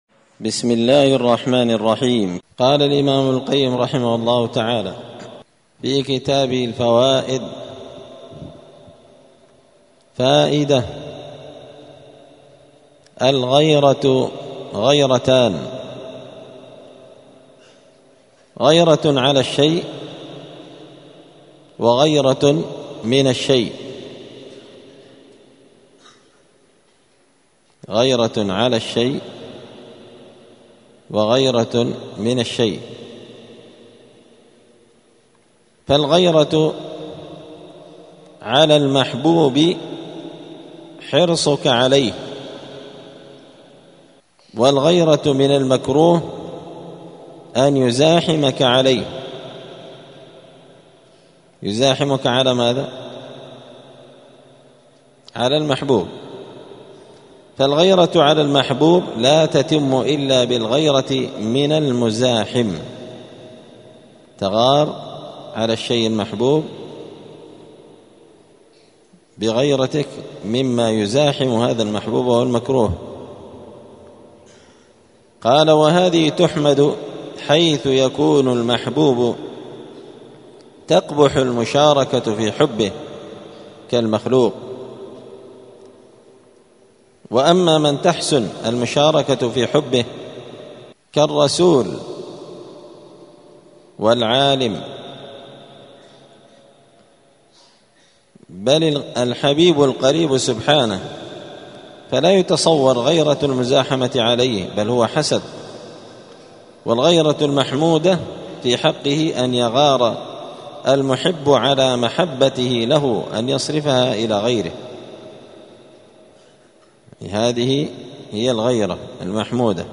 *الدرس الخامس عشر (15) (فصل: الغيرة غيرتان)*